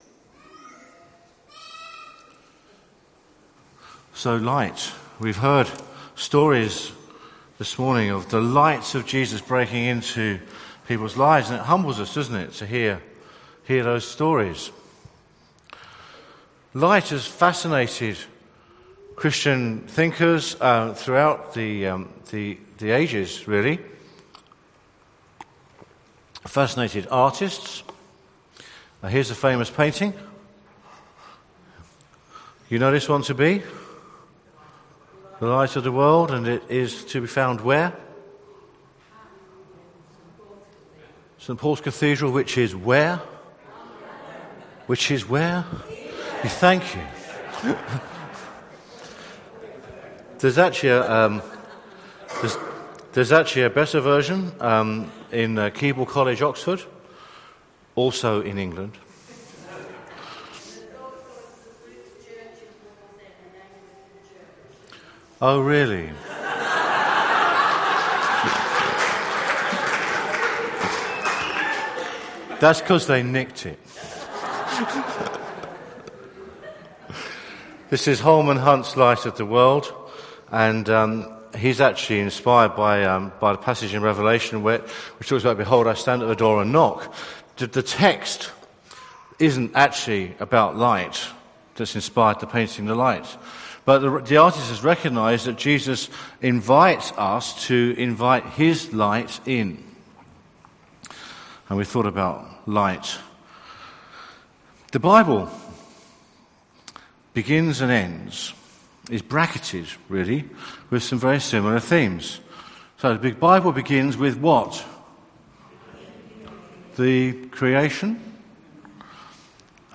Sermon from the 10:00 meeting on the 4th October at Newcastle Worship & Community Centre.